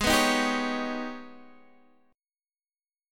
G#mM11 chord